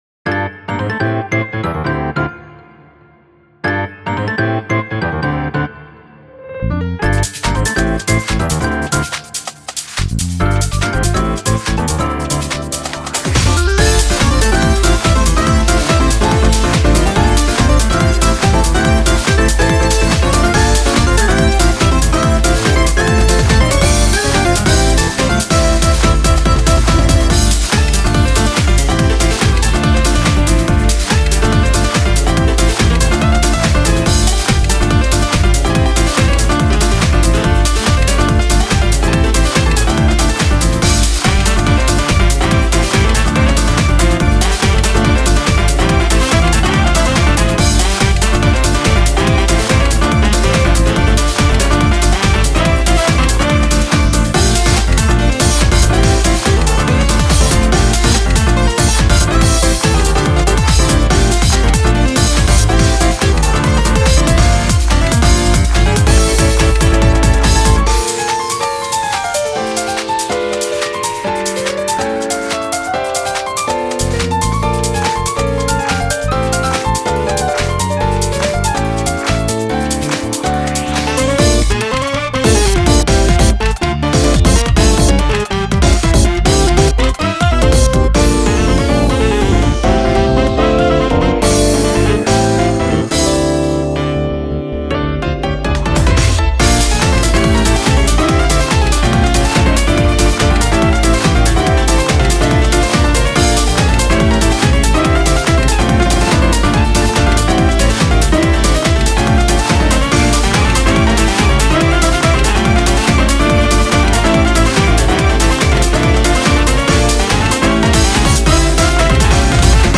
BPM142
Audio QualityPerfect (High Quality)